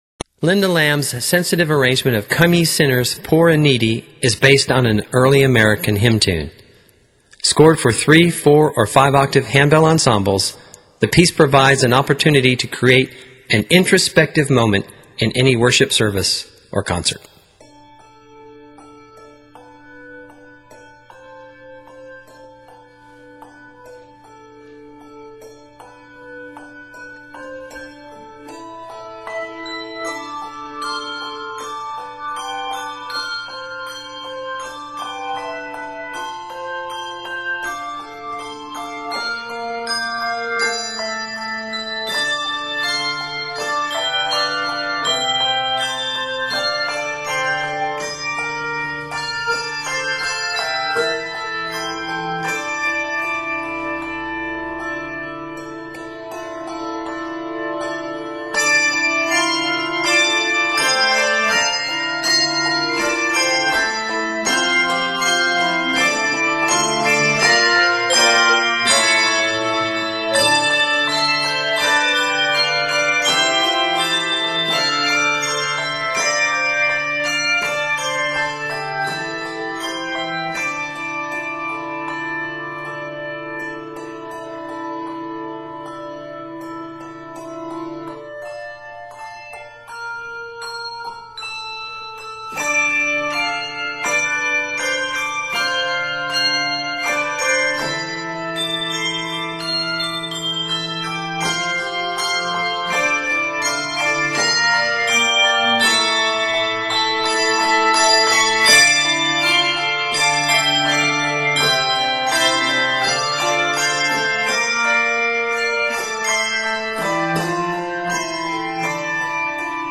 American tune